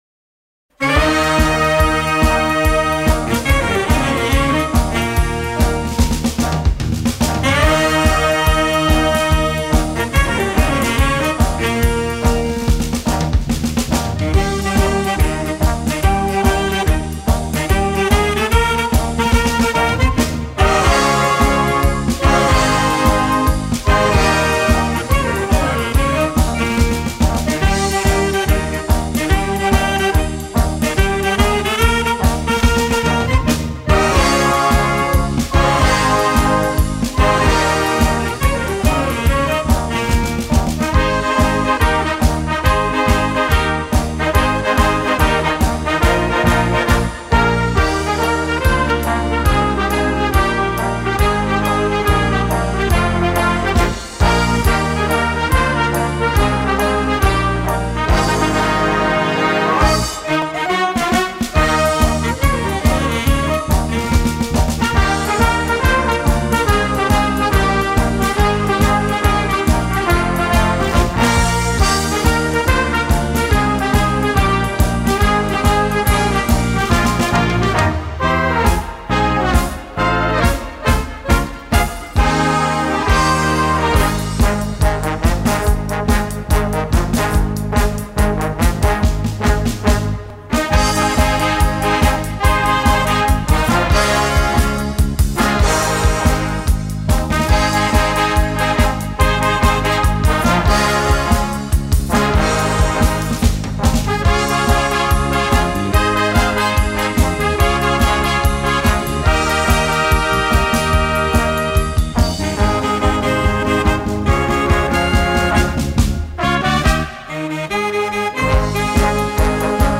Gattung: Medley im Big-Band-Stil
Besetzung: Blasorchester
in einem herrlichen Medley im Big-Band-Stil